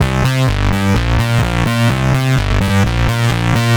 Squeaking Fours C 127.wav